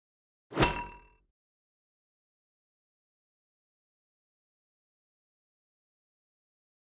Звуки всплывающего окна